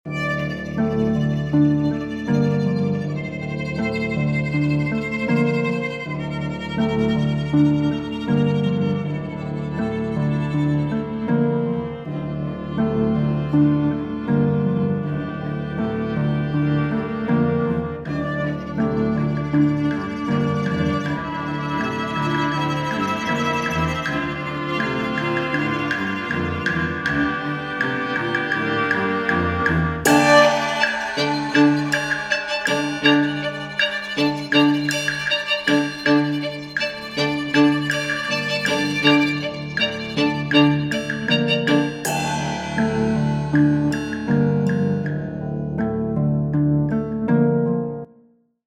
Resource 6: Music - Suspenseful